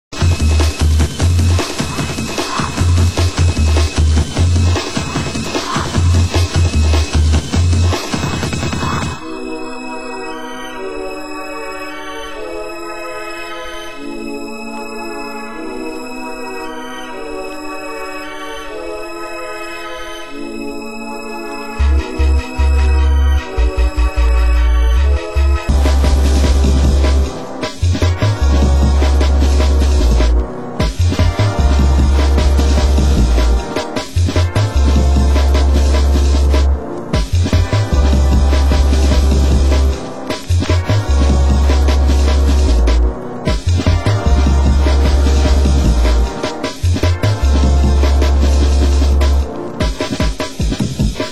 Genre Hardcore